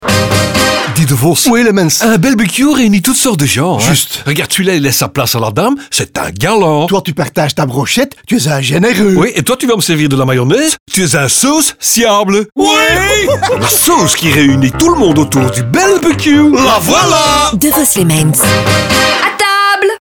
Mais bien entendu, qui dit campagne Devos & Lemmens dit aussi un large assortiment de spots radio.